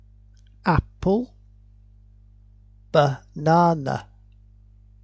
(Apple, a-pel – vowel sound. Banana, bah-nan-a. No vowel sound.)
a-pel-bah-nan-a.mp3